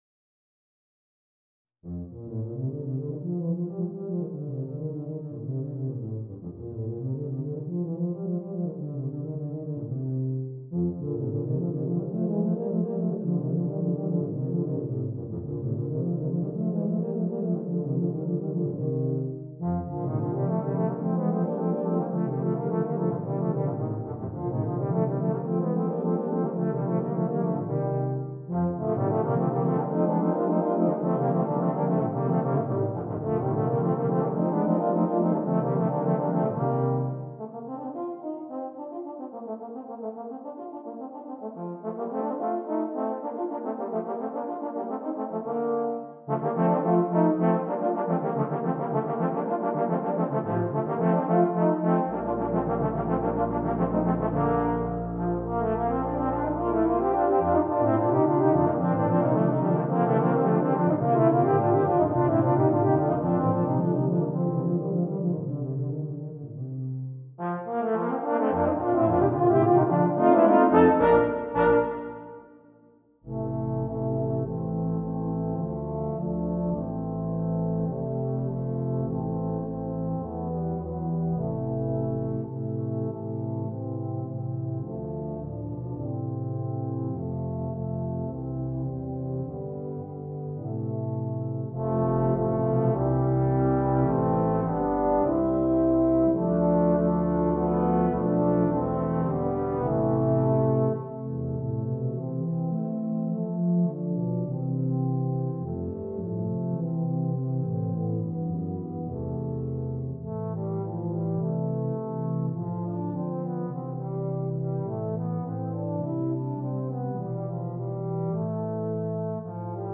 Besetzung: Tuba Quartet